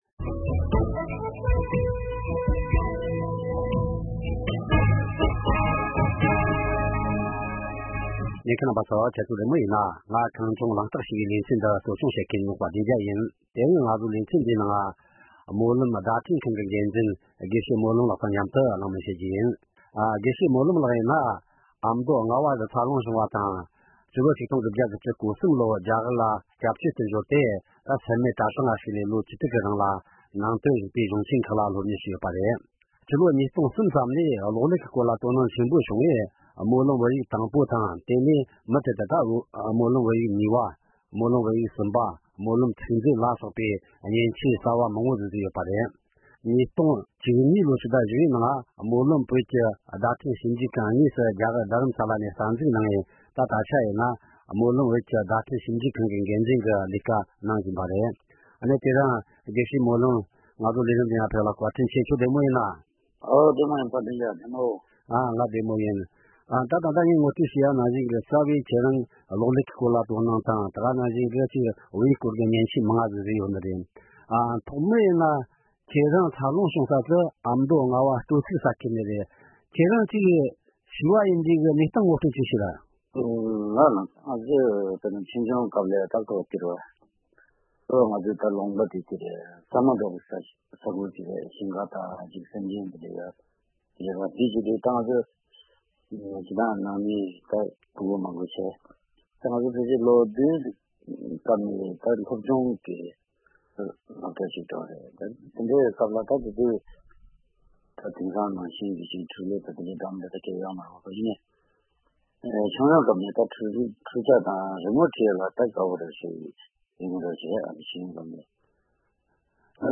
གླེང་མོལ་ཞུས་པར་ཉན་རོགས་ཞུ།